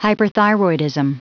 Prononciation du mot hyperthyroidism en anglais (fichier audio)
Prononciation du mot : hyperthyroidism